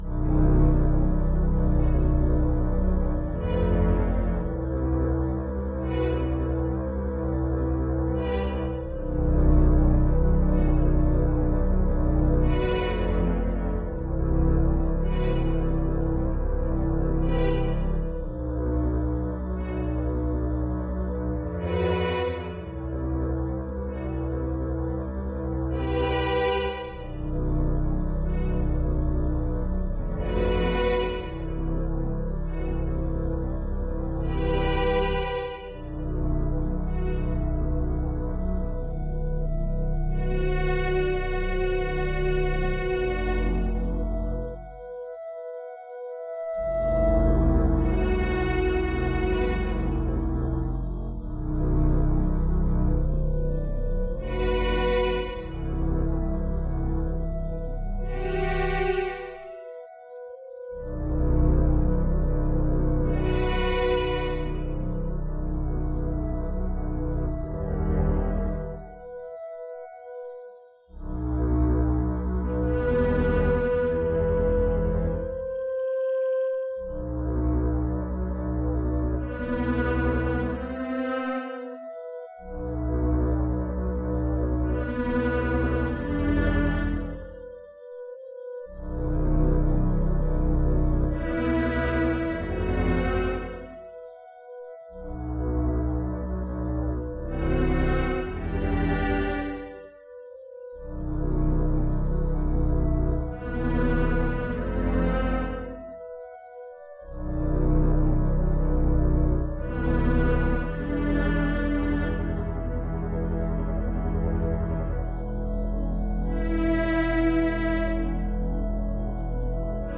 Unsual Strings tune, ever shifting